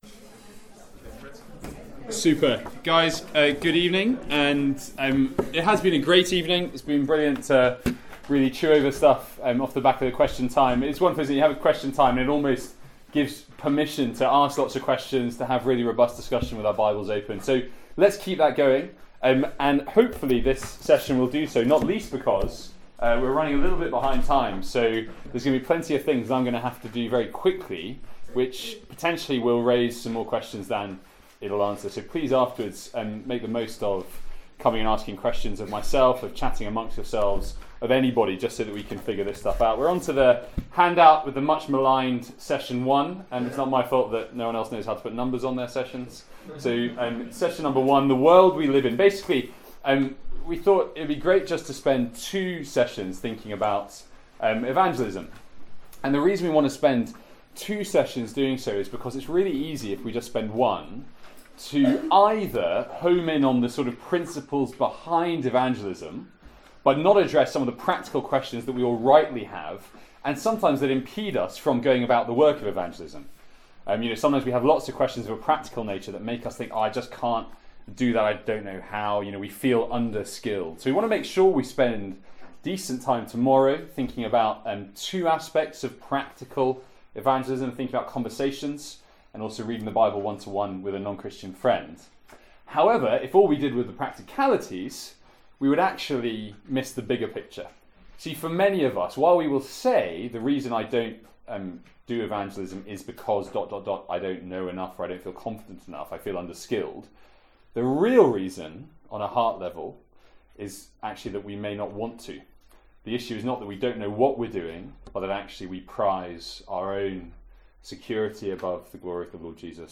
Seminar from MYC15.